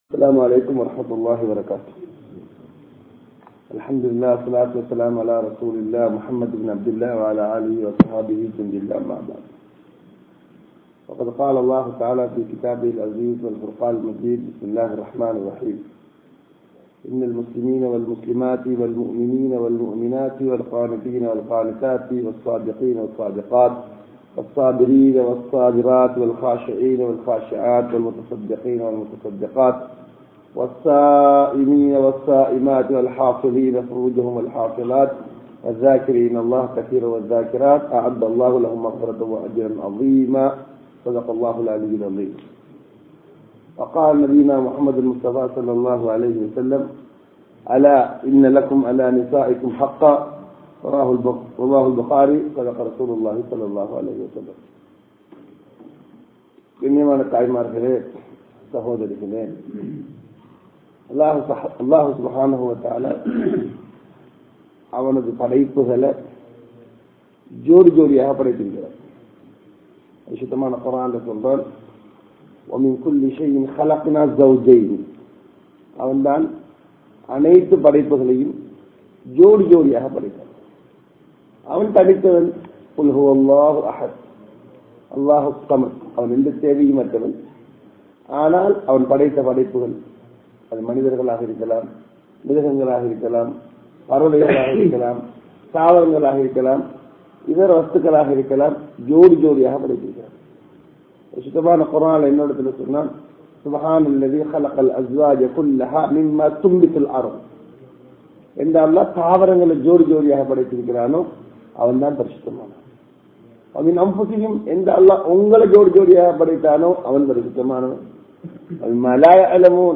Manaivi Kanavanukku Seiya Veandiya Kadamaihal (மனைவி கனவனுக்கு செய்ய வேண்டிய கடமைகள்) | Audio Bayans | All Ceylon Muslim Youth Community | Addalaichenai
UnitedKingdom, Milton Keyness Masjidh